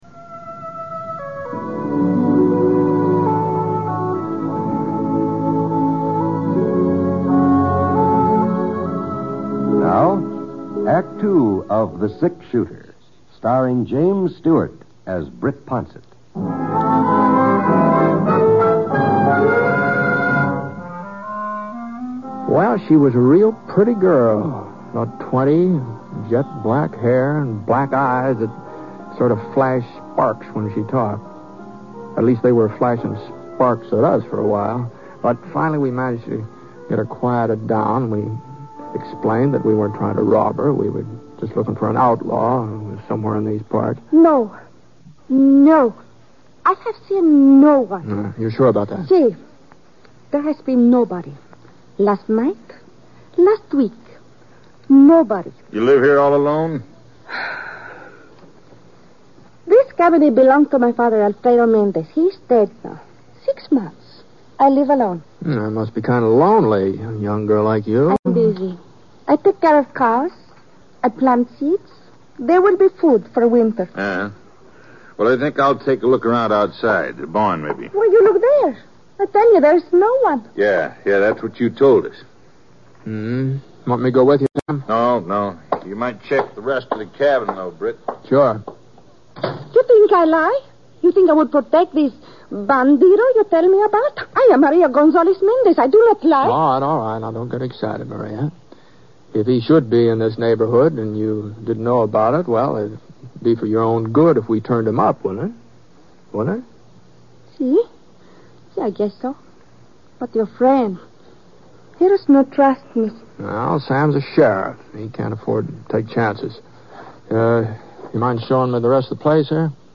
Originally broadcast on April 22, 1954, sixty-two years ago, this show is also sometimes known as Tracking Down a Killer.